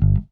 Bass_Stab_03.wav